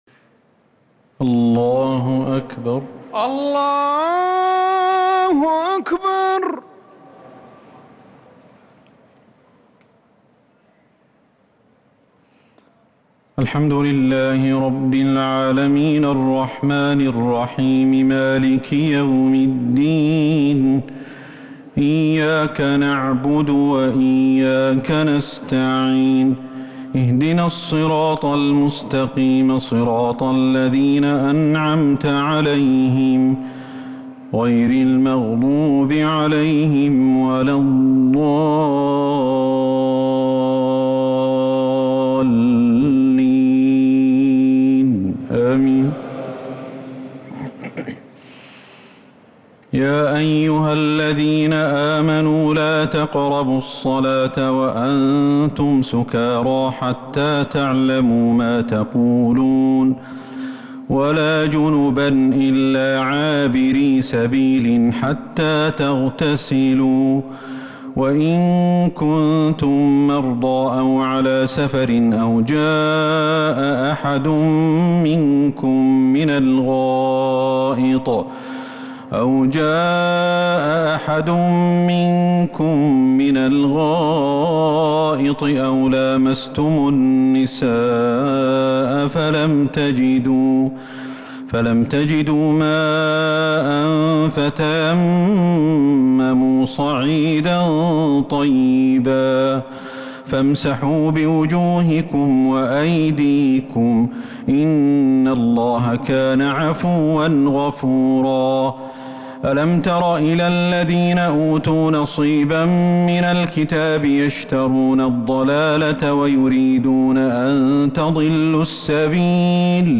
تراويح ليلة 6 رمضان 1443هـ من سورة النساء {43-87} Taraweeh 6st night Ramadan 1443H Surah An-Nisaa > تراويح الحرم النبوي عام 1443 🕌 > التراويح - تلاوات الحرمين